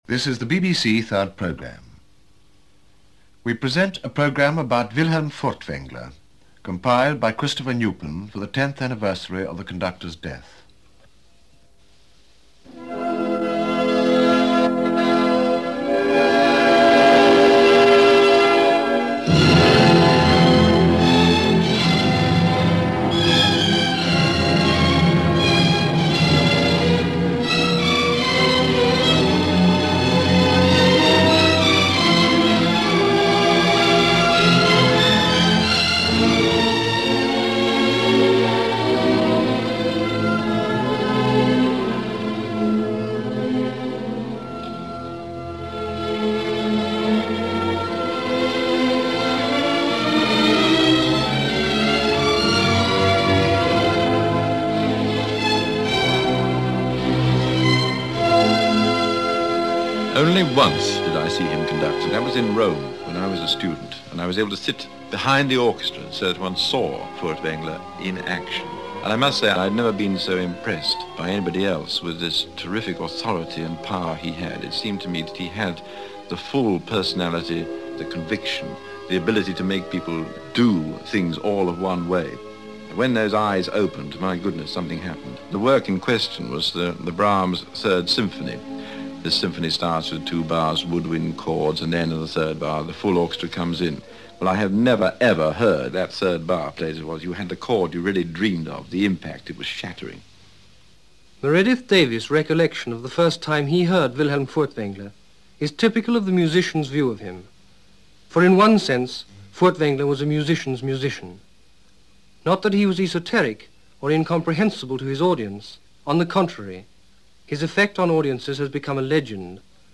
A documentary on the legendary conductor Wilhelm Furtwängler – produced by the BBC 3rd Programme on November 30, 1964, commemorating 10 years since his death.